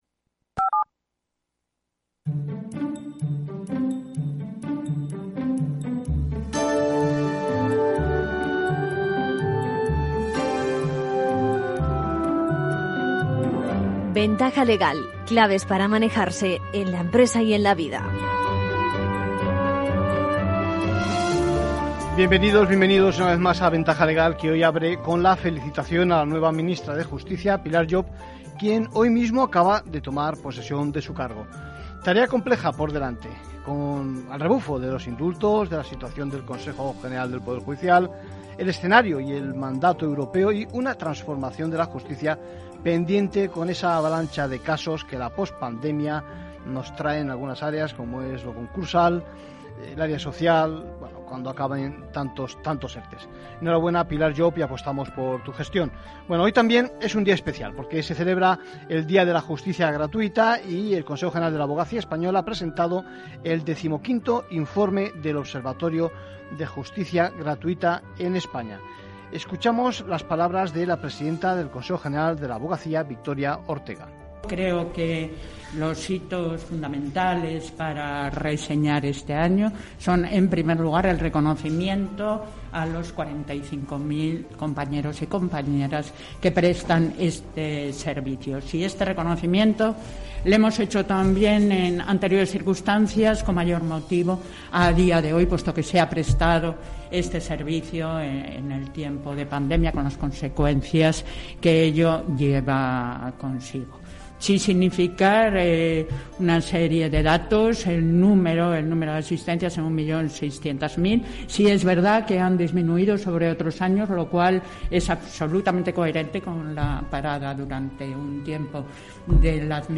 Día de la Justicia Gratuita 2021: Entrevista